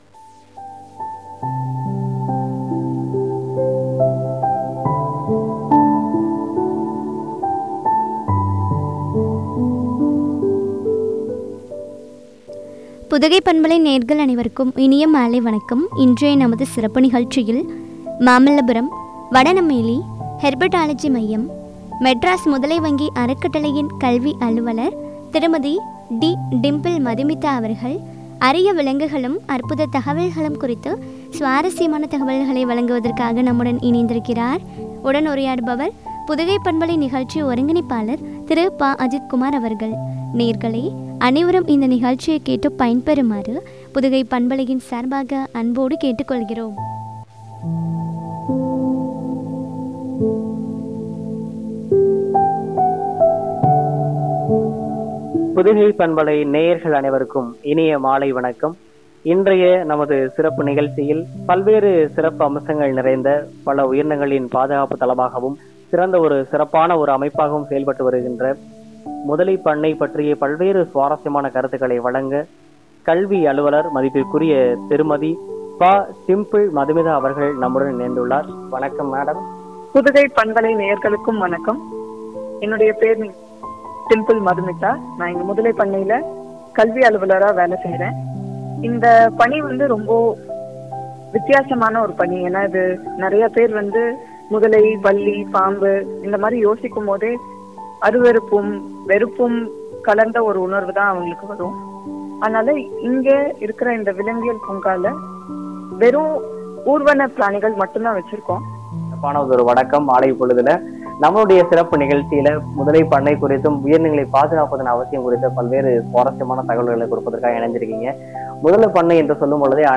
அற்புத தகவல்களும் குறித்து வழங்கிய உரையாடல்.